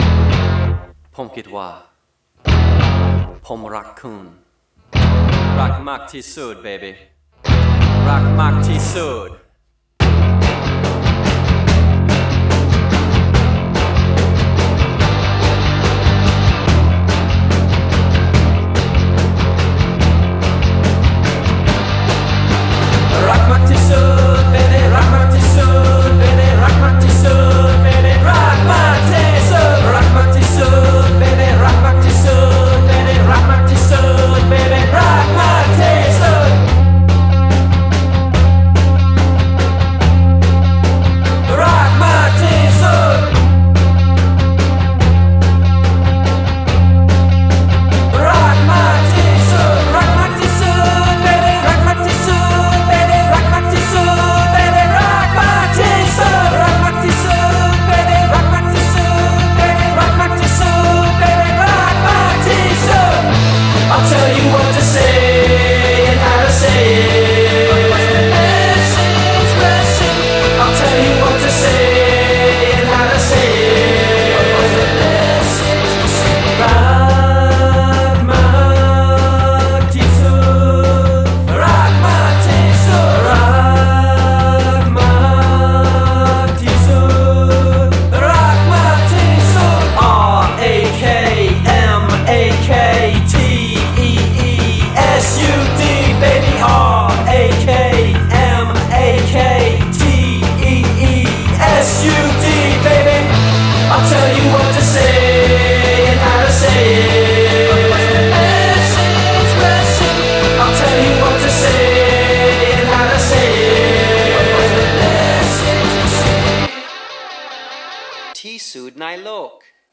good rock sound.
The very end is really sweet. Love the drop out.